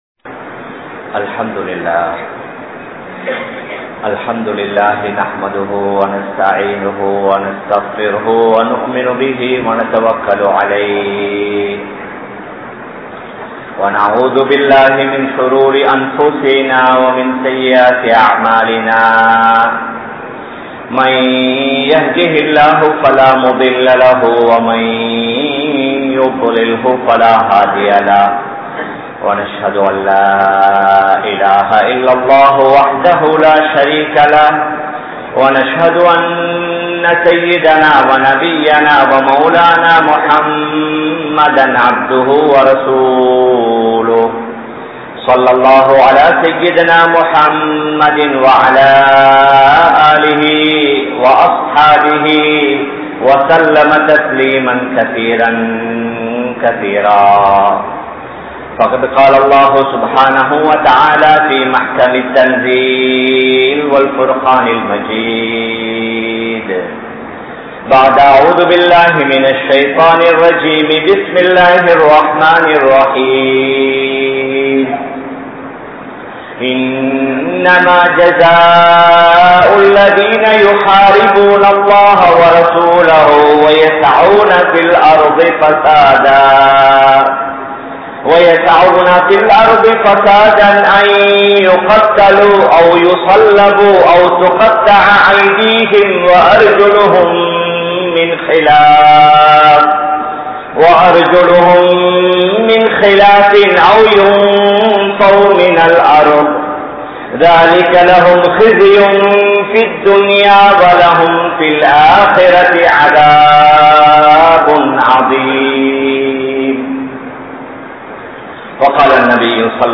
Muminin Panpuhal (முஃமினின் பண்புகள்) | Audio Bayans | All Ceylon Muslim Youth Community | Addalaichenai